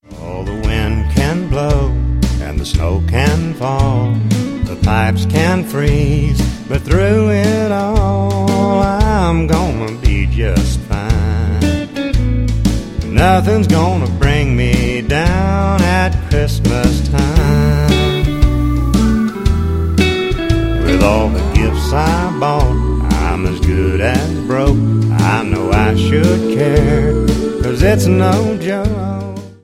• Sachgebiet: Advent/Weihnachten Musik (Christmas)